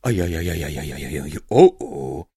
Звуки провала, неудачи
Звук, де чоловік засмутився (ай-яй-яй)